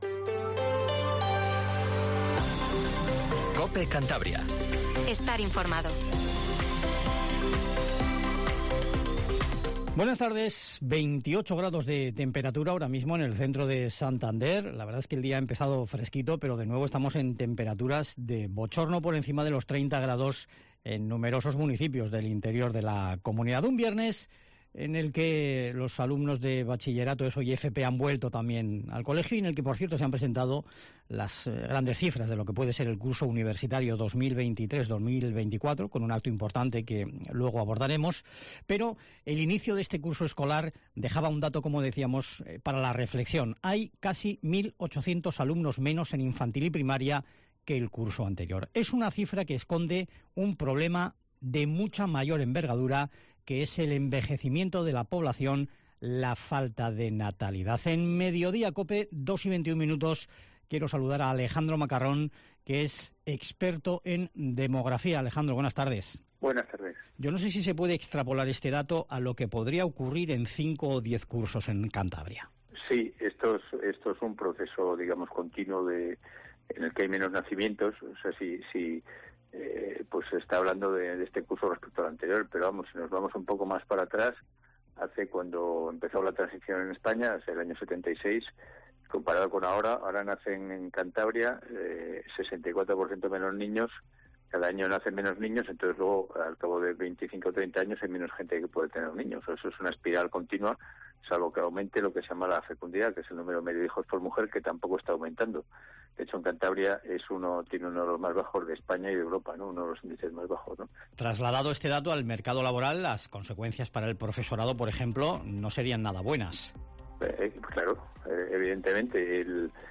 Informativo Regional 14:20